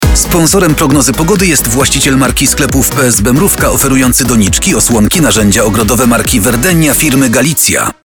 • 8-sekundowe wskazania sponsorskie, które można było usłyszeć w stacjach: RMF FM, RMF Maxxx, RMF Classic, RMF ON, Radio ZET, Antyradio oraz Meloradio.